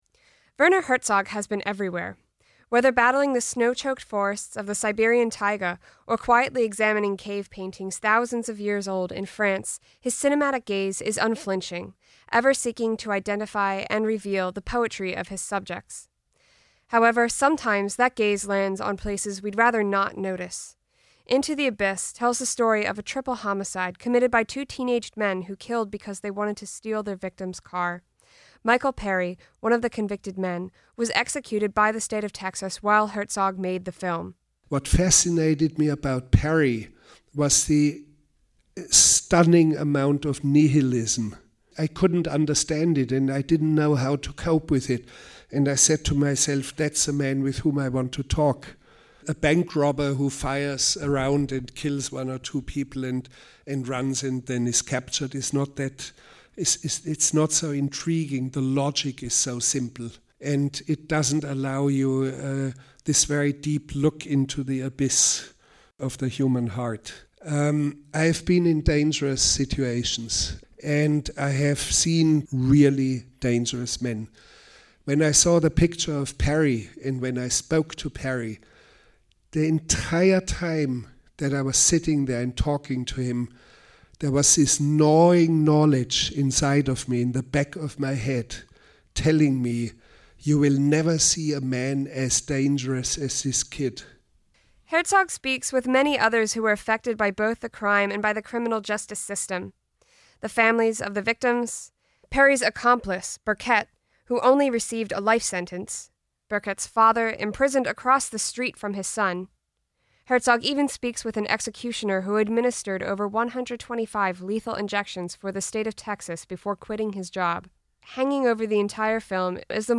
Filmmaker Werner Herzog was at the Aero Theatre in Santa Monica where the American Cinematheque screened “Into the Abyss,” a documentary about two men convicted of a triple homicide.